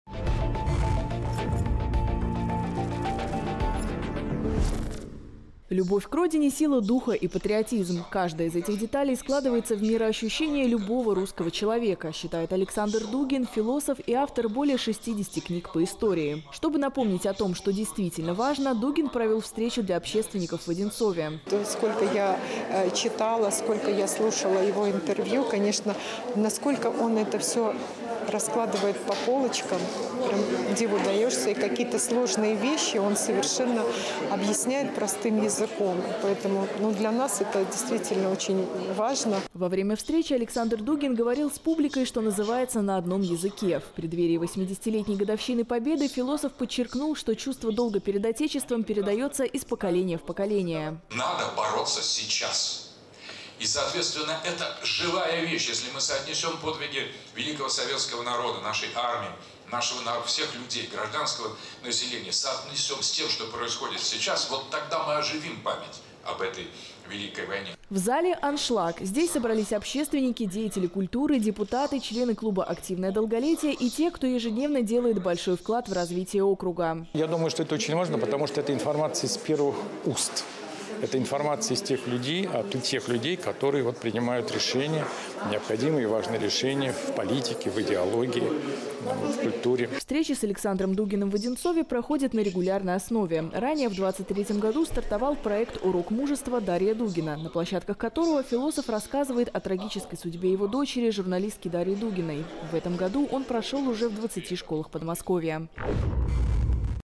Александр Дугин провел лекцию для общественников в Одинцове // 360 Одинцово
В Одинцовском округе в актовом зале администрации прошла встреча депутатов муниципалитета, общественников, руководителей и сотрудников сферы образования с философом, политологом и социологом Александром Дугиным.